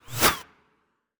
bullet_flyby_05.wav